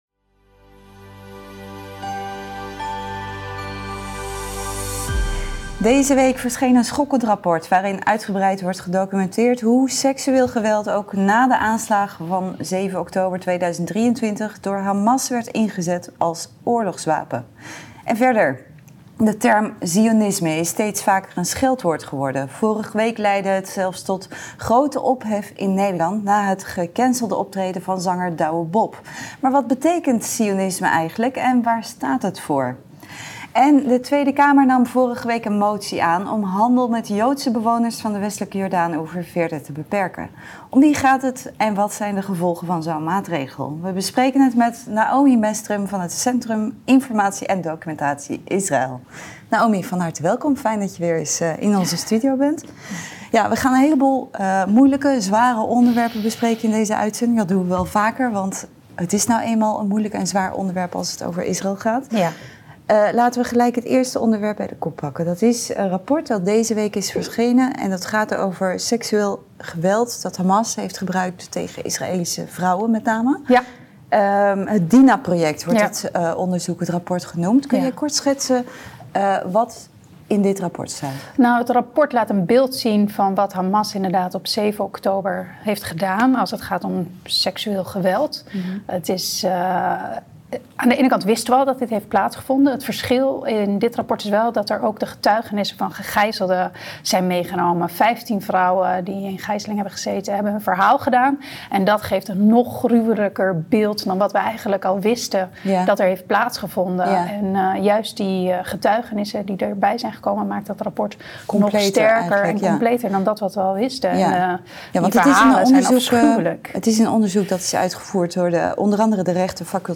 Uitzending 9 juli